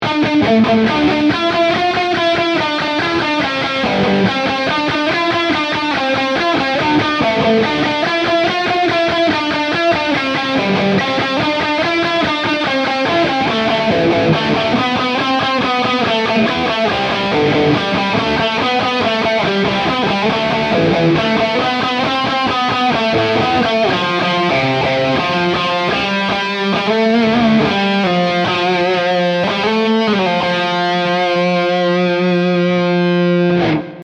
Tutte le clip audio, sono state registrate con testata a Marshall JCM800 sul canale Low e cassa 2×12 equipaggiata con altoparlanti Celestion Creamback, impostata su un suono estremamente clean.
Chitarra: Gibson Les Paul (pickup al ponte)
Mode: Lead 2
Gain: 8/10